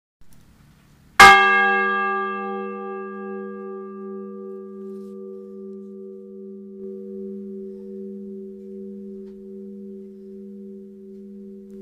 cloche n°1 - Inventaire Général du Patrimoine Culturel
Enregistrement sonore de la cloche